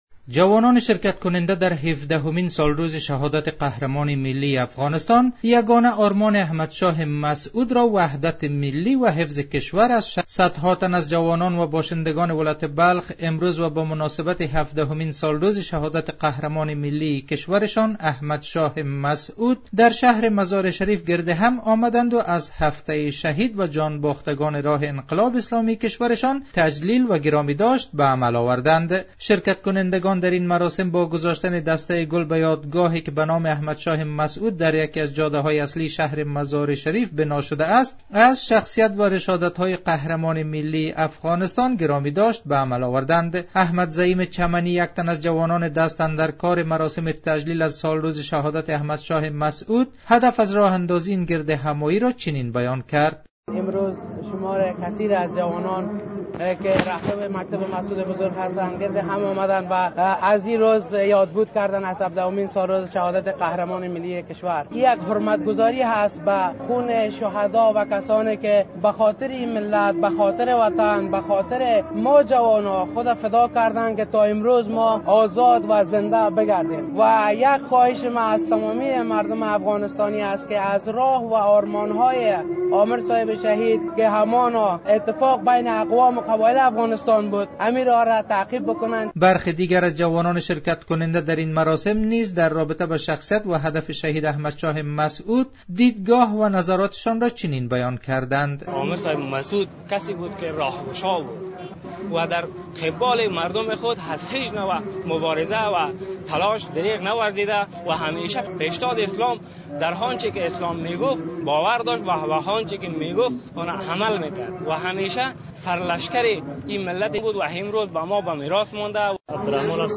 به گزارش خبرنگار رادیو دری،صدها تن از جوانان و باشندگان ولایت بلخ امروز به مناسبت هفدهمین سالروز شهادت قهرمان ملی افغانستان در شهر مزار شریف گرد هم آمدند و از هفته شهید تجلیل کردند.